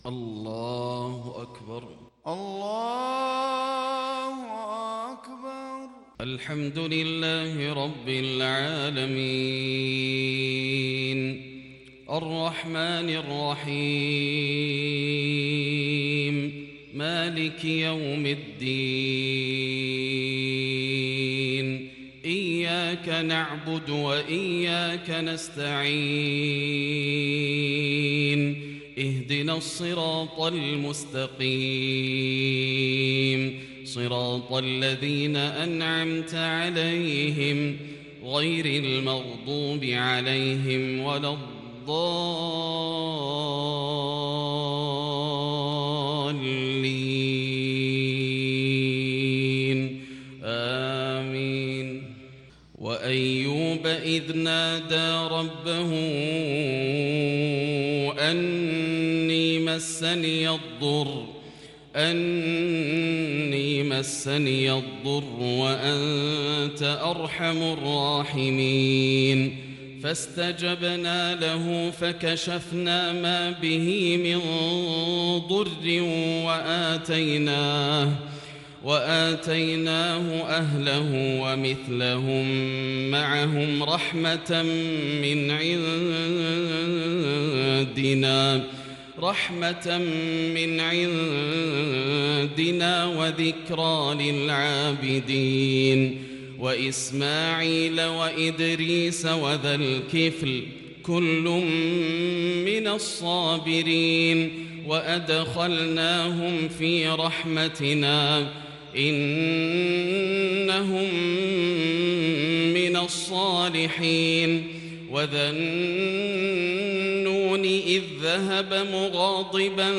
صلاة الفجر للشيخ ياسر الدوسري 19 صفر 1442 هـ
تِلَاوَات الْحَرَمَيْن .